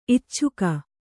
♪ icchuka